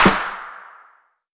TC3Snare9.wav